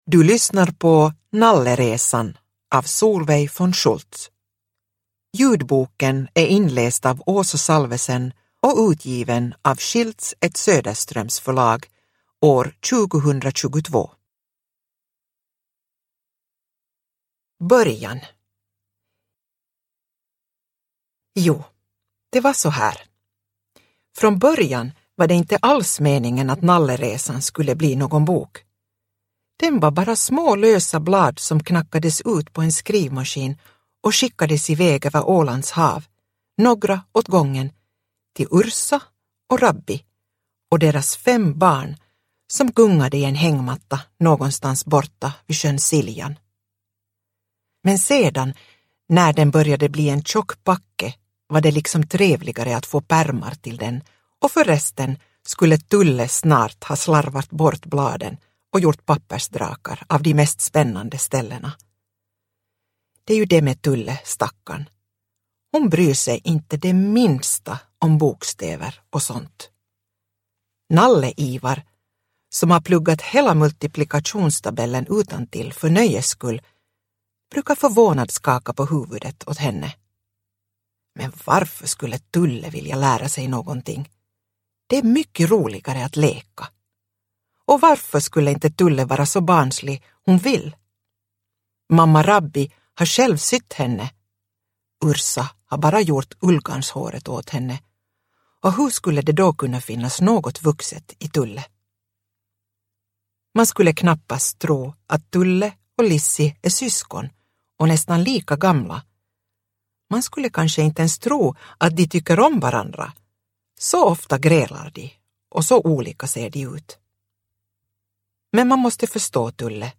Nalleresan – Ljudbok – Laddas ner